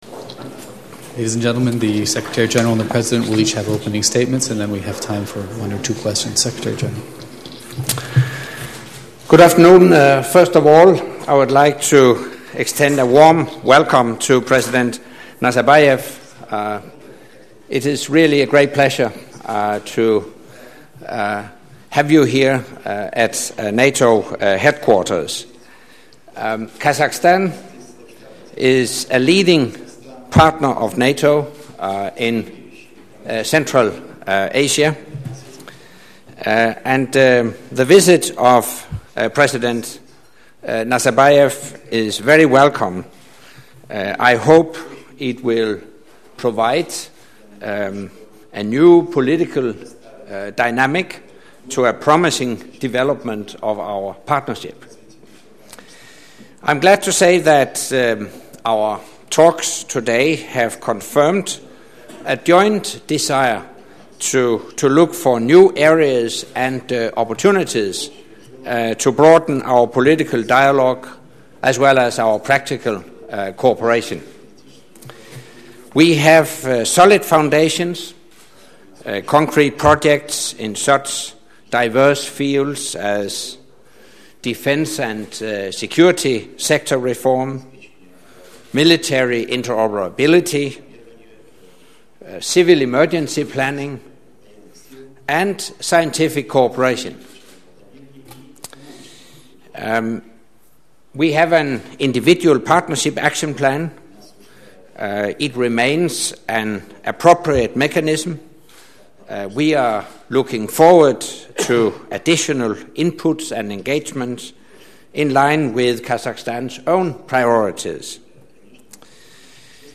Joint press point with NATO Secretary General Anders Fogh Rasmussen and the President of Kazakhstan, Mr. Nursultan Nazarbayev